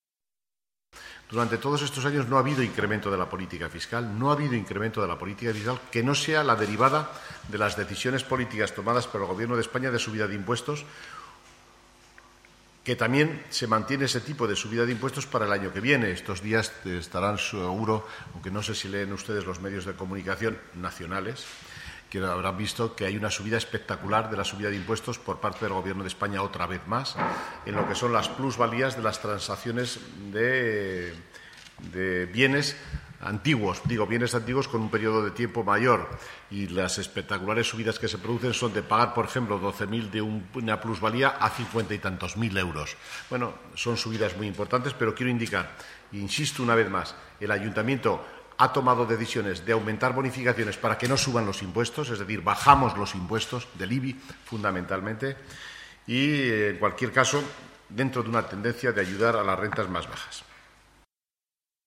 En este sentido, para que en 2015 paguen una cantidad similar en su recibo del IBI (el principal impuesto municipal), se incrementa la bonificación de la cuota en un 8%. Sobre esta cuestión, el vicealcalde y portavoz del Gobierno, Fernando gimeno, ha manifestado: